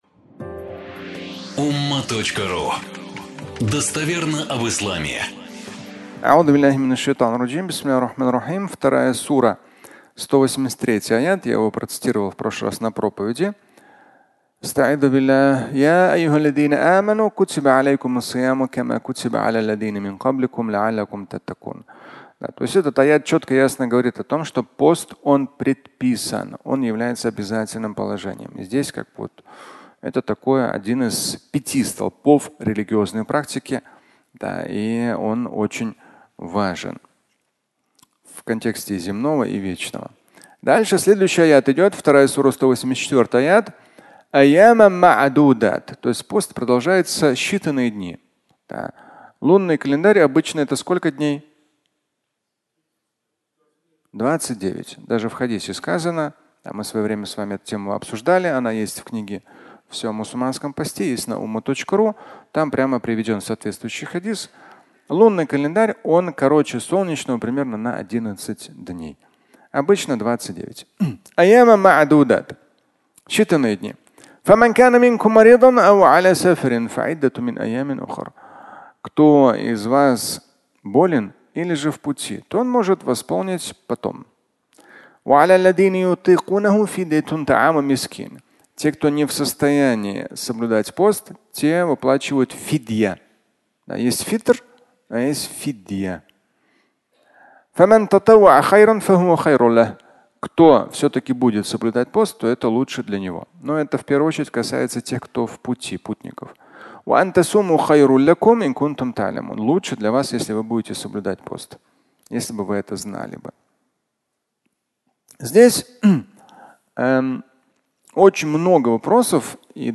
Болезнь и пост (аудиолекция)